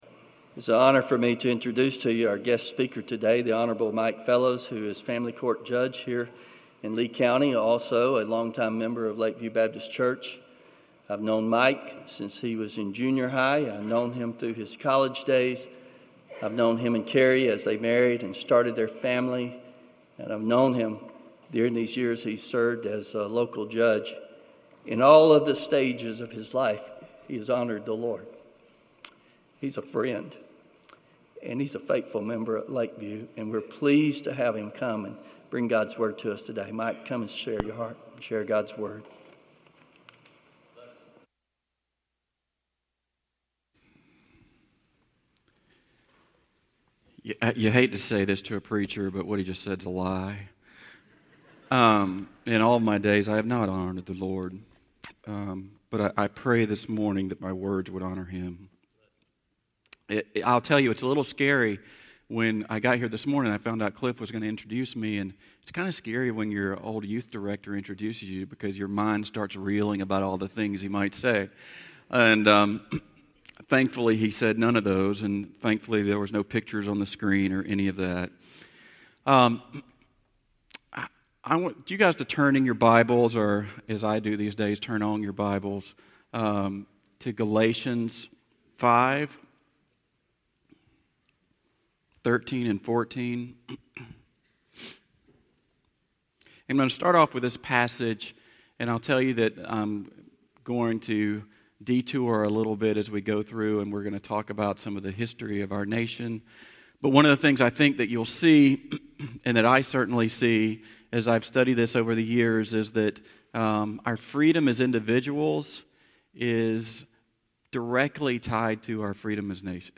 God & Country Day Service